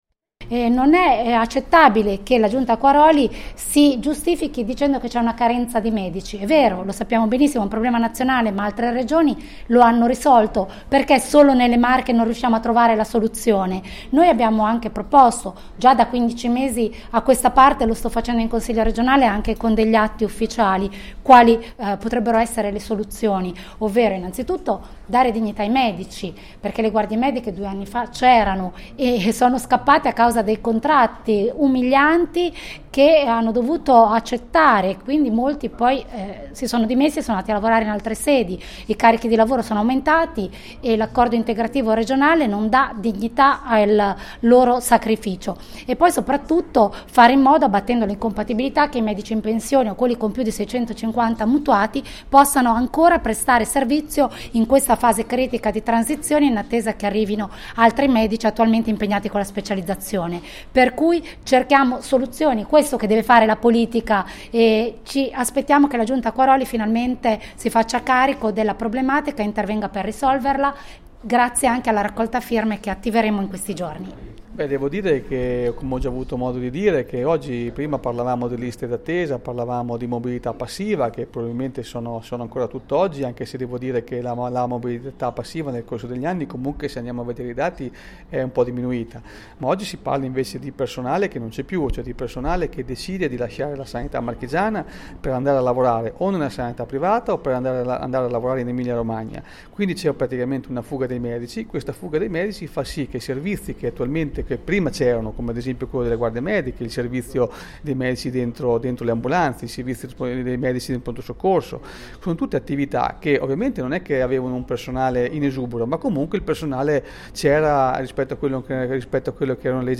Presentata la mobilitazione del Partito Democratico a favore della riapertura e/o ripristino del servizio di guardia medica in tutte le sedi chiuse o che hanno subito la riduzione dell’orario, nel territorio provinciale. Ci parlano dell’importante iniziativa i Consiglieri Regionali Micaela Vitri e Andrea Biancani.